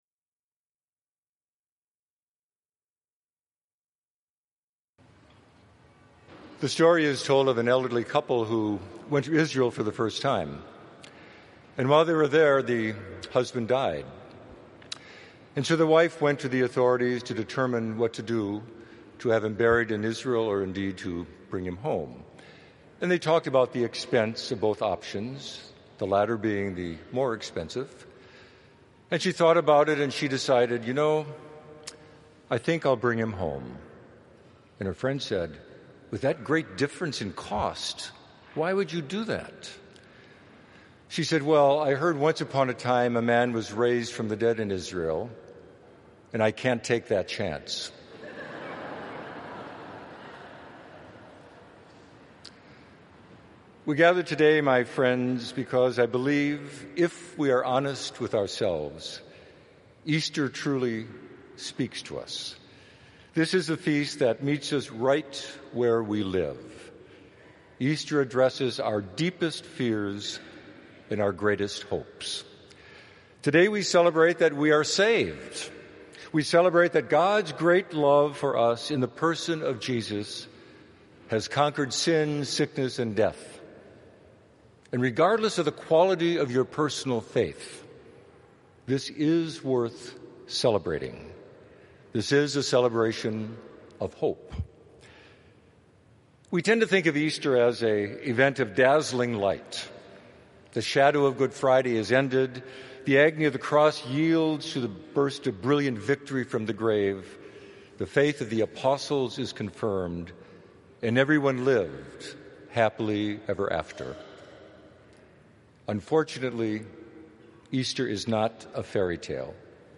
Homily
Easter Sunday 11:30 am Celebration